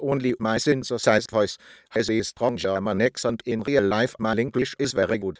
CHATR's German synthesis)